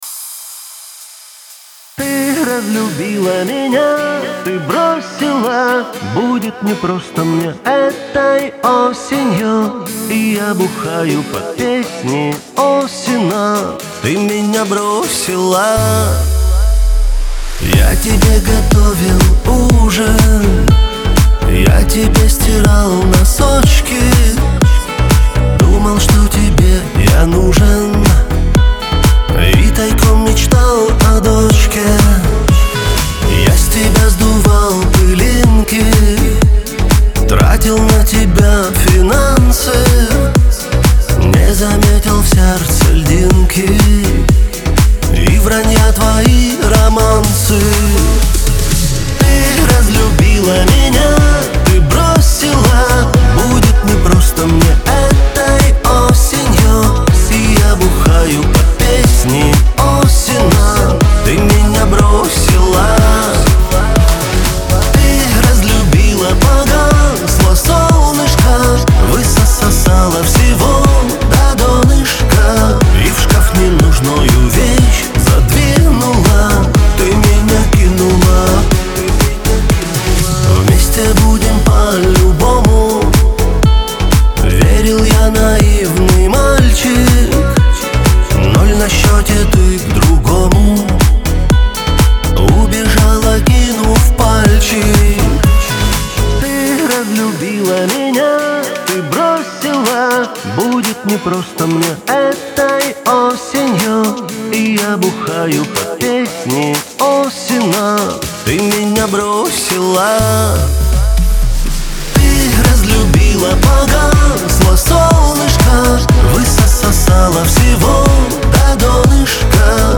диско , эстрада
pop